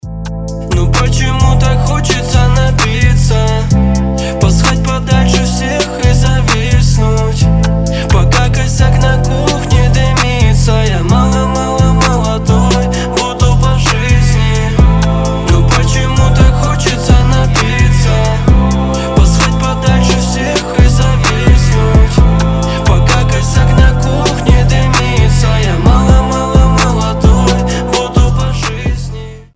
• Качество: 320, Stereo
русский рэп